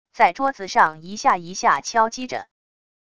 在桌子上一下一下敲击着wav音频